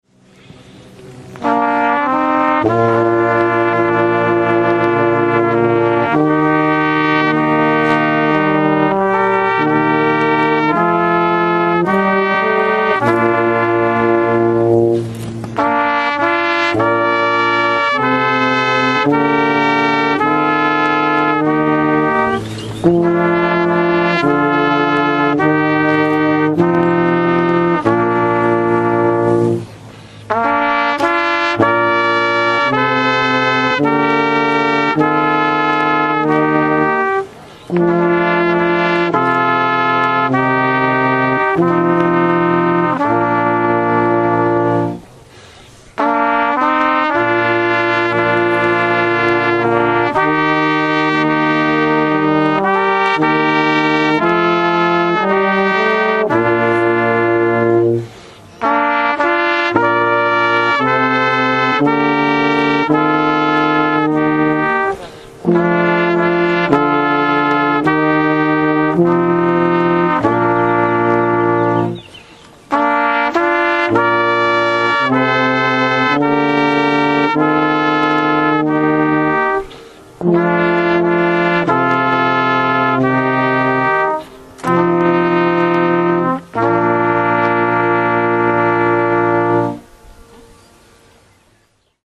Ob štirinajsti obletnici ene najhujših nesreč v zgodovini slovenskega gorskega reševanja je bil danes, 10. junija 2011, spominski pohod na Okrešelj.
slika z nastopa kvarteta trobil Policijskega orkestra, Turska gora, 2011Slovesnost so pospremili zvoki žalostink kvarteta trobil Policijskega orkestra.